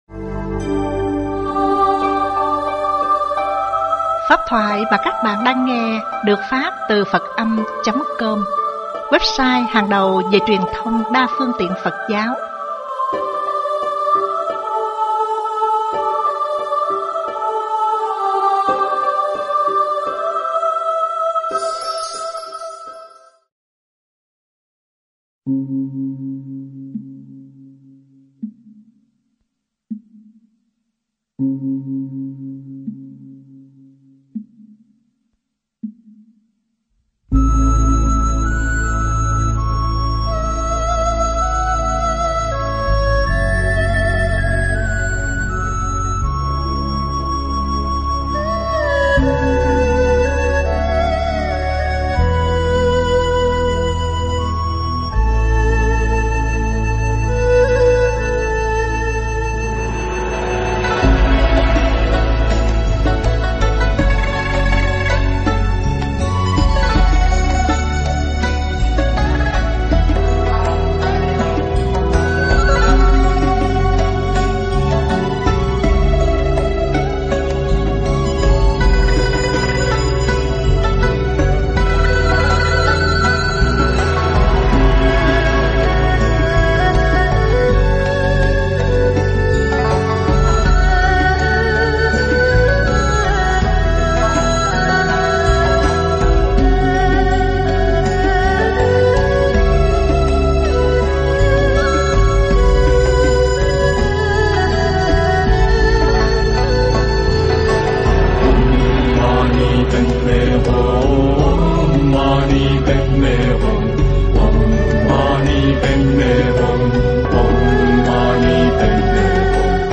Nghe mp3 thuyết pháp "The Two Bricks